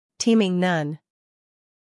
英音/ ˈtiːmɪŋ / 美音/ ˈtiːmɪŋ /